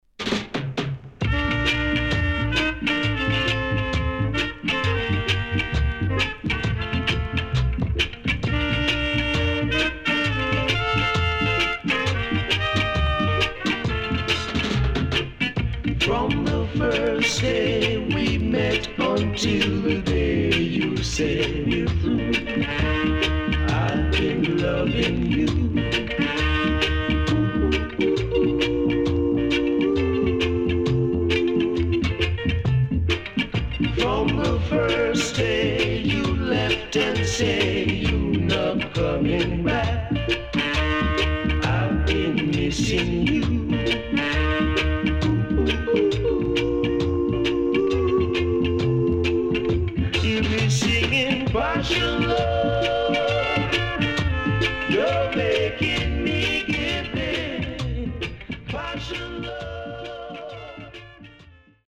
名曲 W-Side Great Rocksteady Vocal
SIDE A:うすいこまかい傷ありますがノイズあまり目立ちません。